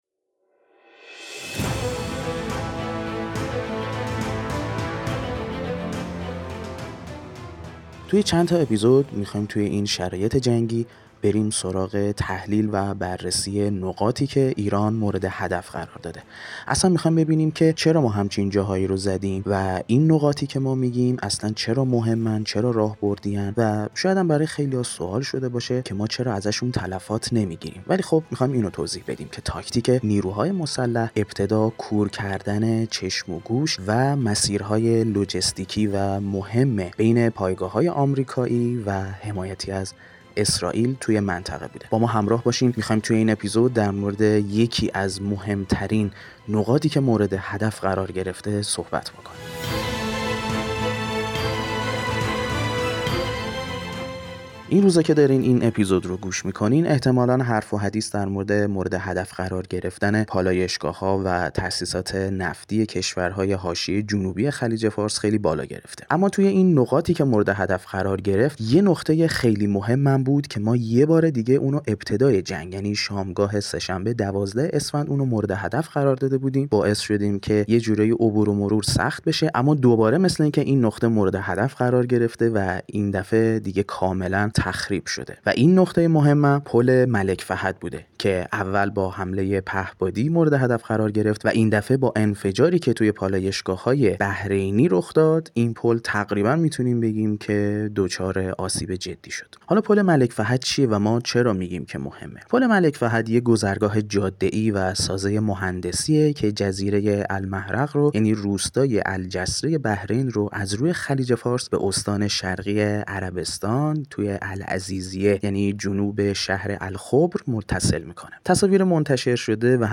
آناکست؛ مستند